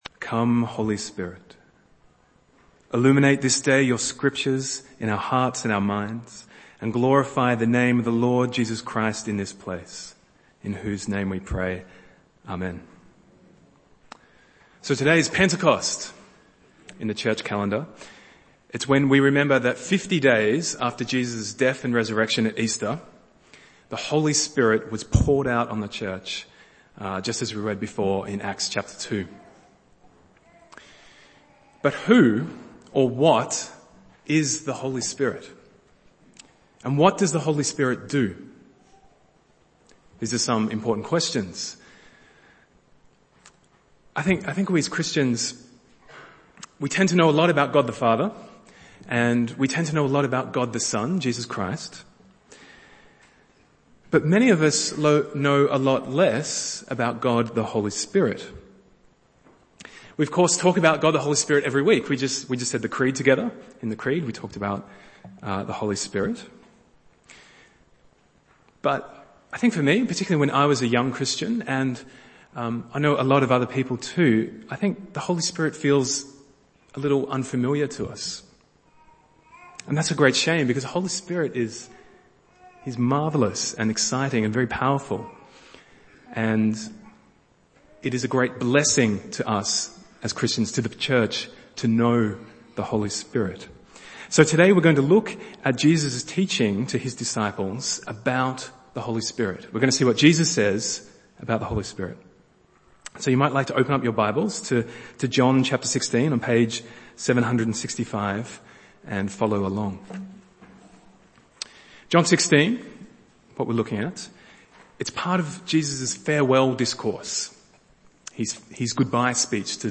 Bible Text: Acts 2:1-21 | Preacher